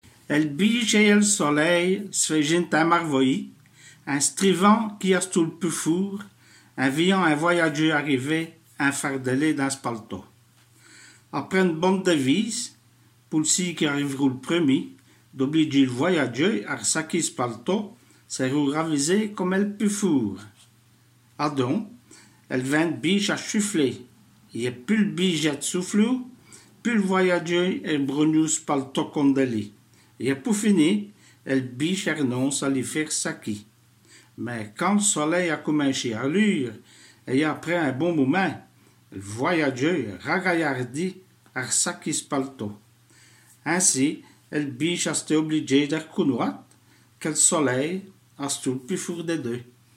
Roci, vos ploz schoûter èn eredjistrumint del fåve do vî vî tins « Li bijhe et l' solea » ratourné avou l' accint d' après Les Scåssenes pol Djåzant atlasse éndjolike des lingaedjes di France et d' avår la .